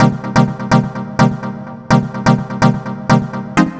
Stab 126-BPM C.wav